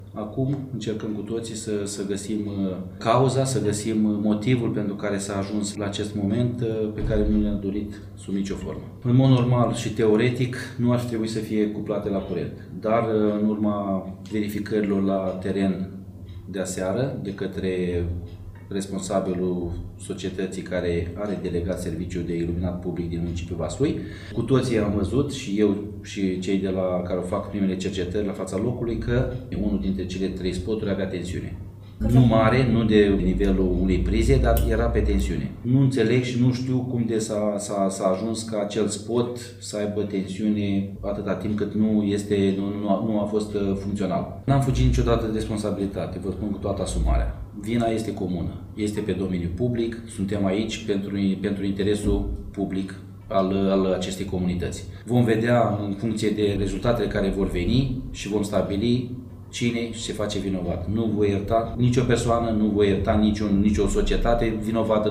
Primarul municipiului Vaslui, Lucian Branişte, a anunțat că au fost începute verificări în cazul copilului de 1 an şi cinci luni, care a murit electrocutat după ce a atins un spot luminos amplasat pe o zonă cu spaţiu verde din Piaţa Centrului Civic.